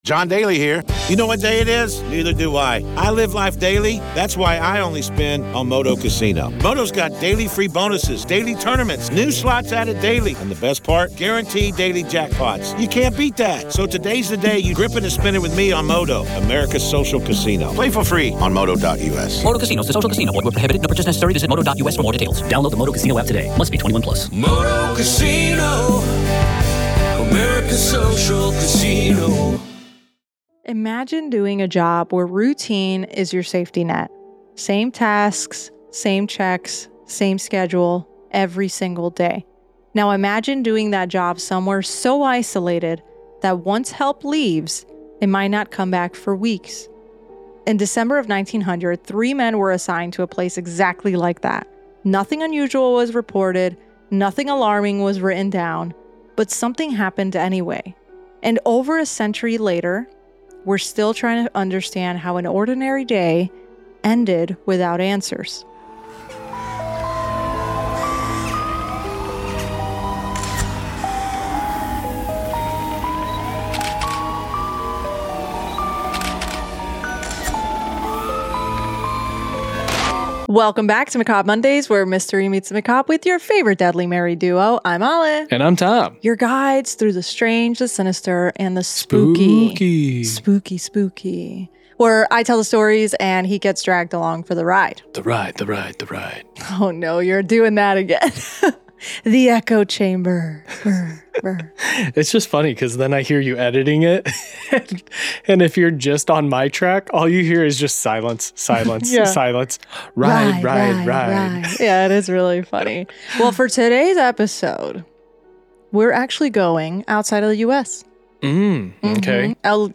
Disclaimer: Macabre Mondays is a true crime and paranormal podcast created for informational and entertainment purposes only.